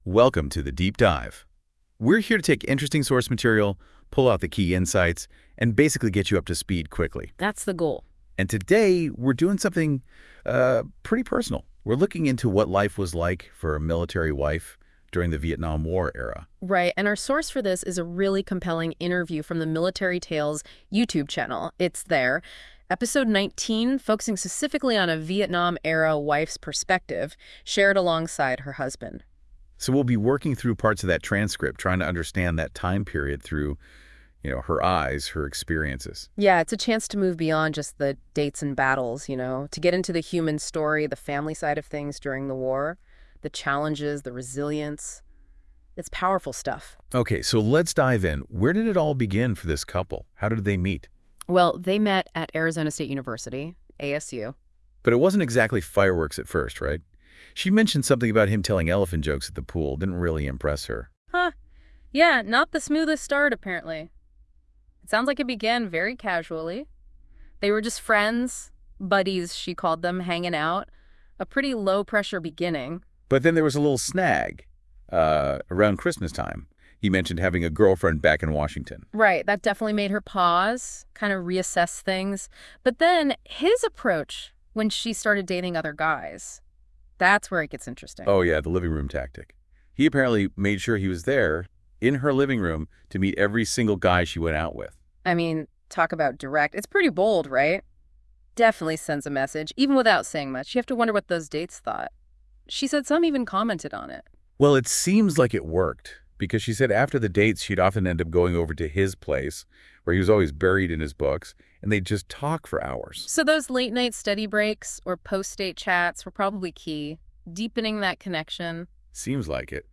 Episode 019 - Interview with a Vietnam era wife - Military Tales